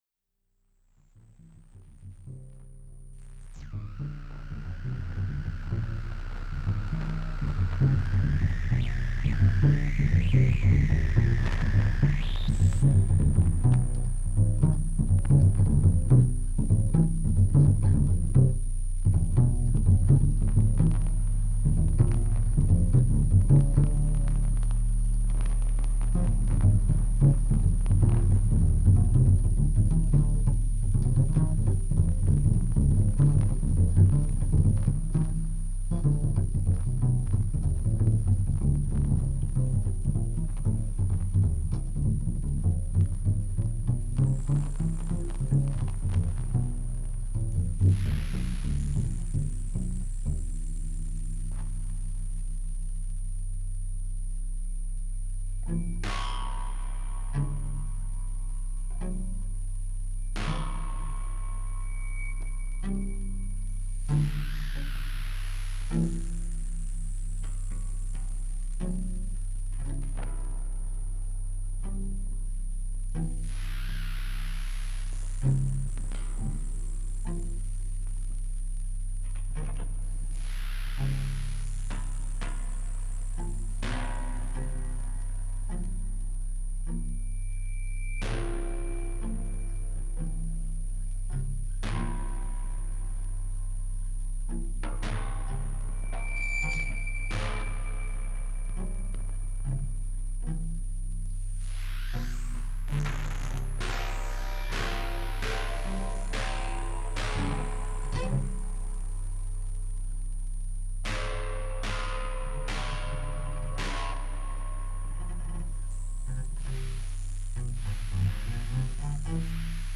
Deep noise drones set against upright bass, piano and drums in varying degrees... free jazz for this imprisoned time